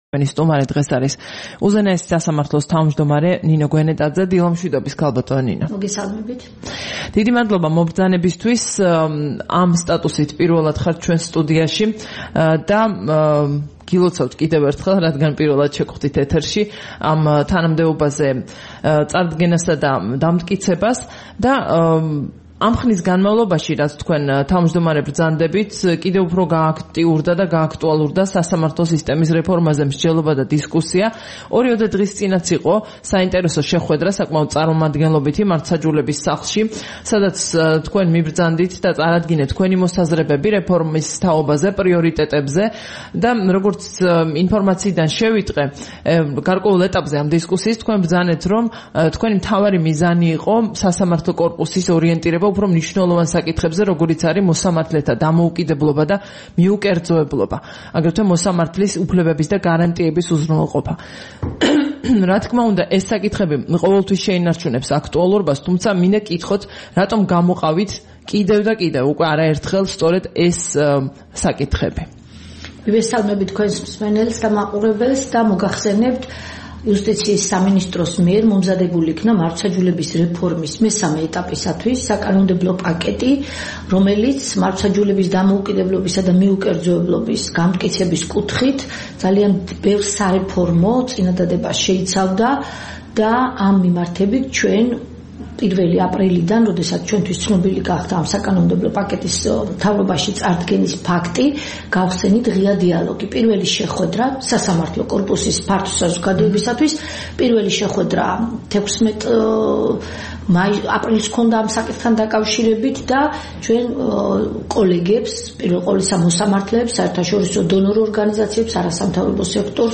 საუბარი ნინო გვენეტაძესთან